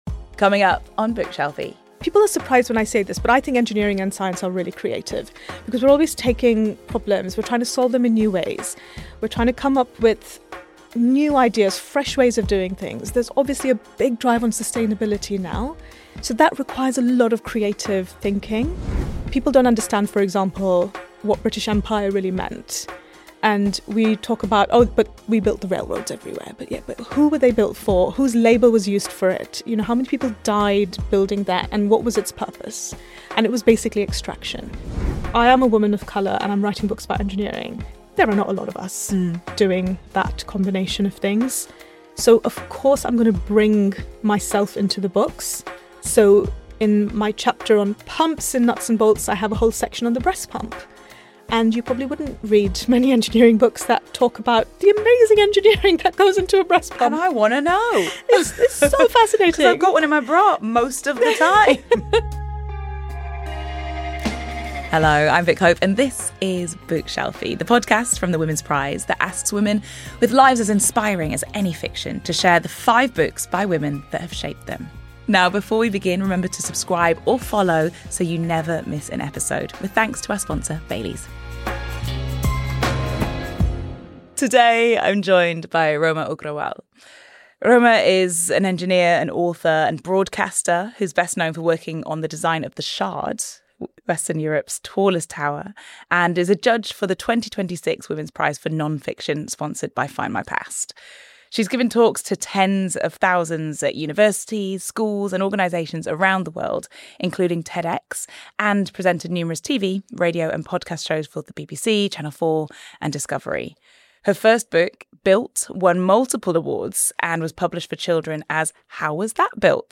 Engineer, author and broadcaster Roma Agrawal talks to Vick about reclaiming her body after childbirth, centring the knowledge of non-western cultures and why engineering is more creative than you think.